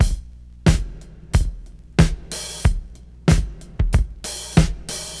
• 92 Bpm Drum Loop Sample E Key.wav
Free drum loop - kick tuned to the E note.
92-bpm-drum-loop-sample-e-key-Lht.wav